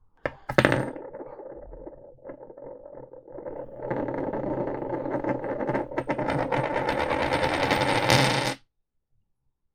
Coin Spin - 4
buy coin coins ding drop dropping falling finance sound effect free sound royalty free Sound Effects